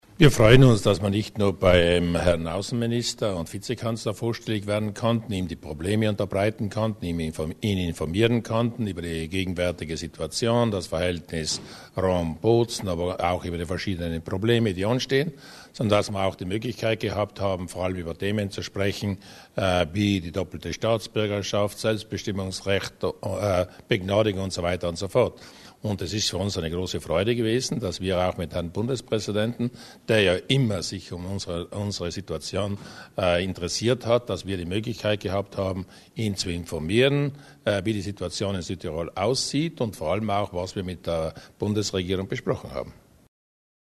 Bundespräsident Fischer zu den behandelten Themen